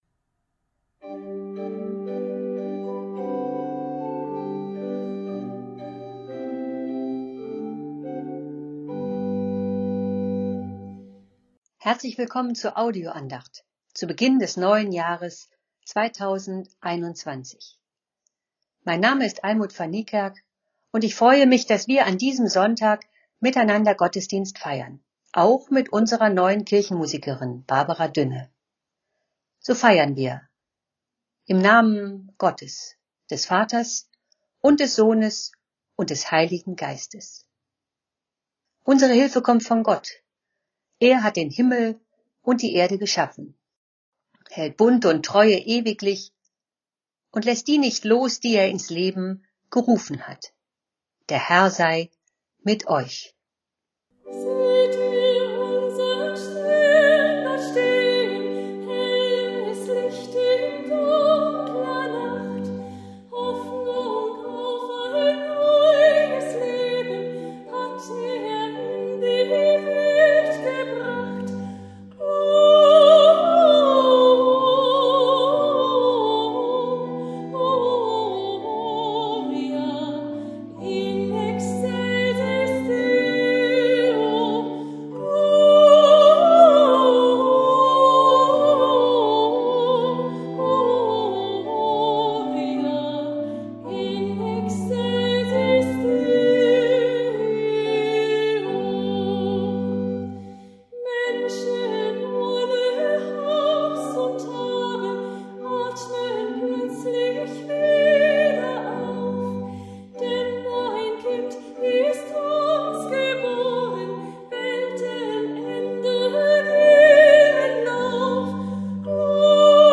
Audio-Andacht zum Sonntag